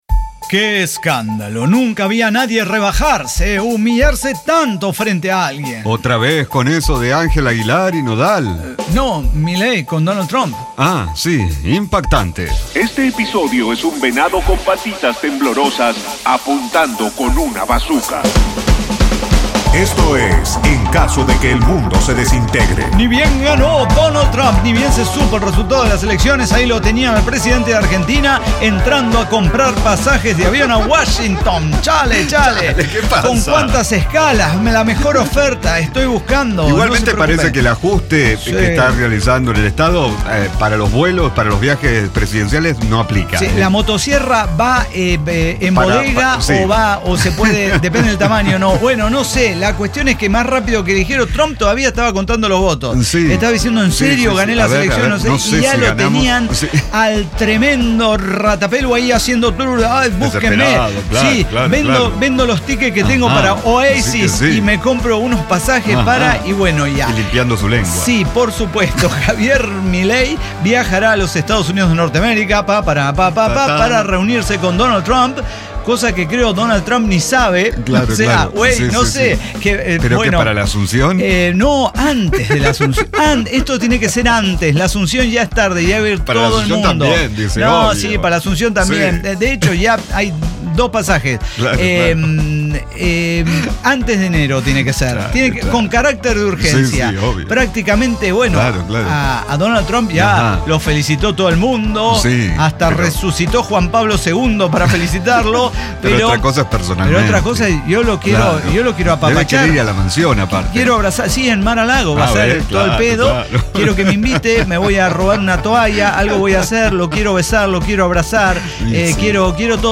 ECDQEMSD podcast El Cyber Talk Show – episodio 5910 Recuerdos de Hawaii
Diseño, guionado, música, edición y voces son de nuestra completa intervención humana.